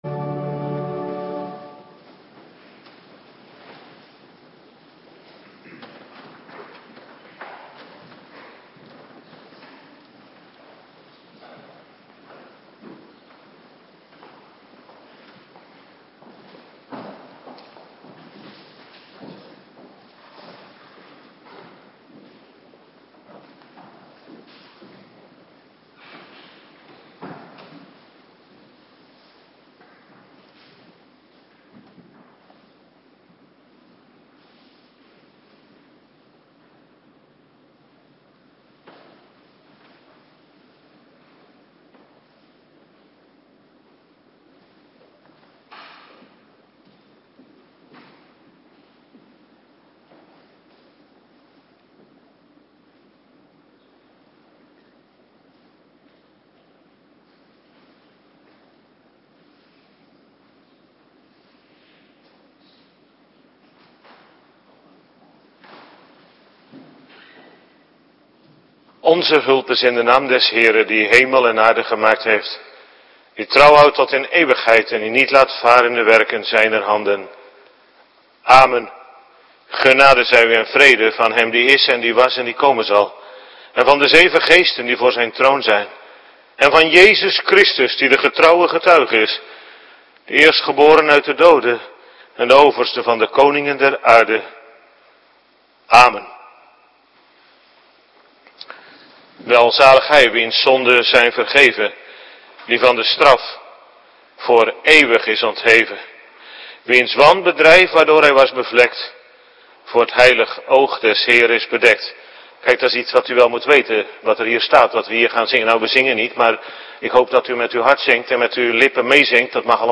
Avonddienst - Cluster 1
Locatie: Hervormde Gemeente Waarder